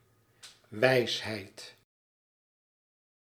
Ääntäminen
IPA : [ˈseɪ.ɪŋ]